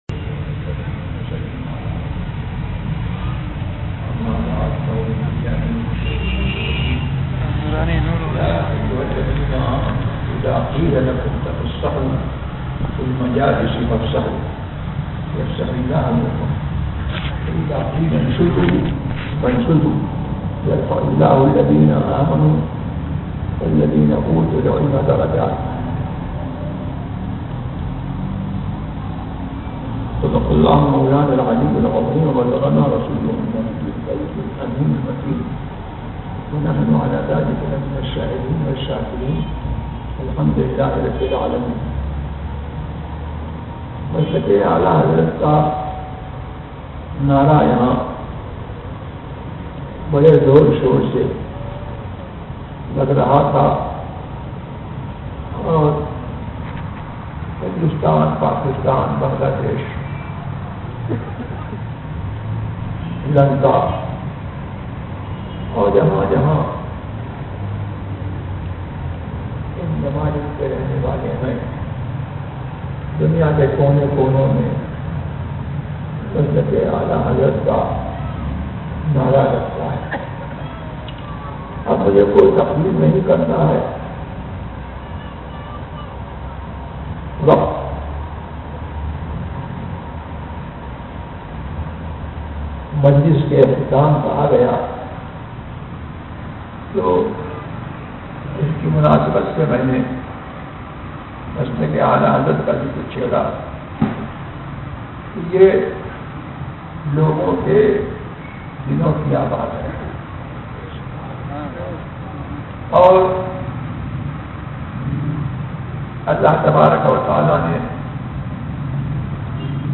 Khitab At Karachi on 5 August 2008
Category : Speeches | Language : Urdu